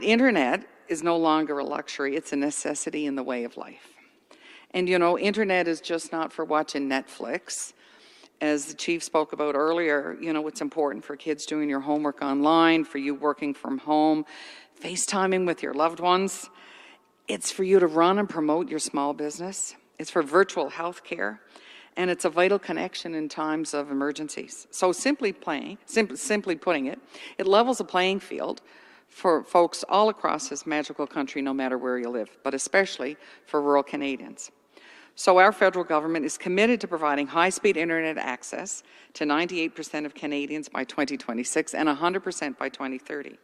Federal Minister of Rural Economic and Development Gudie Hutchings emphasized the important of internet in today’s connected world.